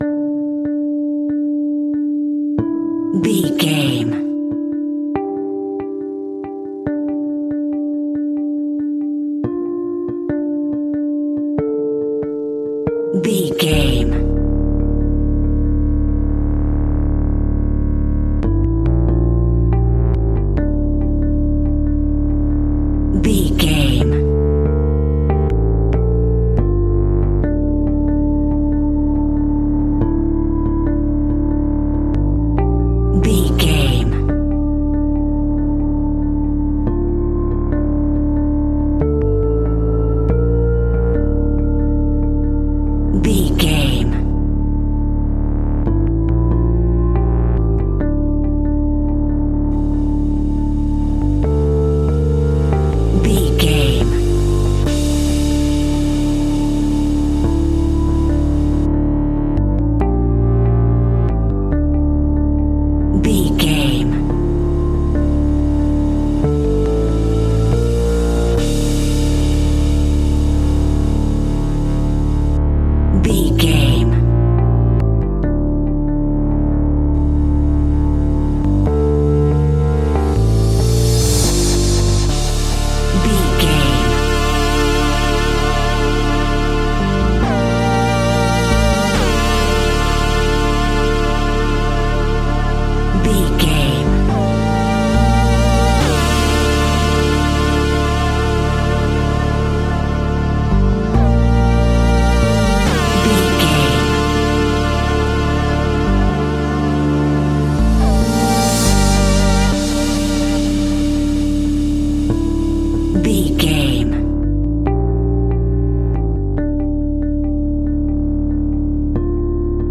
Aeolian/Minor
scary
ominous
dark
disturbing
haunting
eerie
synthesiser
percussion
suspenseful
electronic music
electronic instrumentals